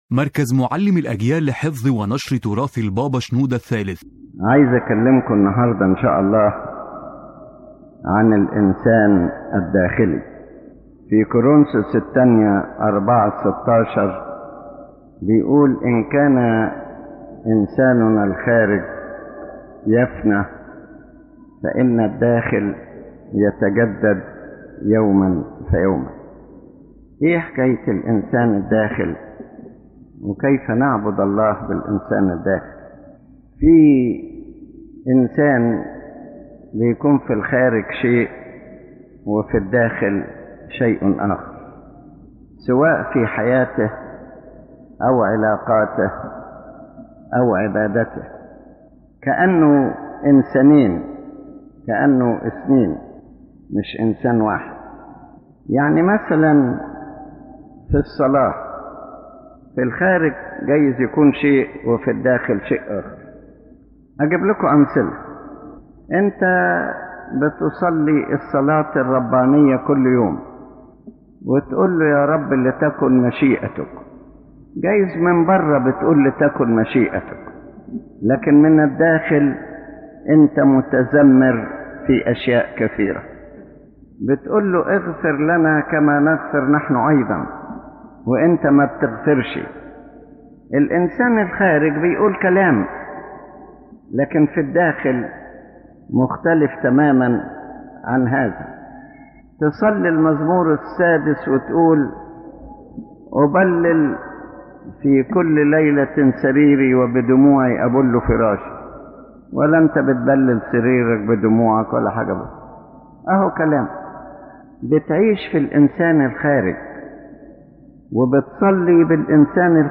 This lecture by His Holiness Pope Shenouda III focuses on the importance of the inner person in spiritual life, affirming that God does not look at outward appearances or spoken words alone, but at the heart and the inner depth from which all actions and worship flow.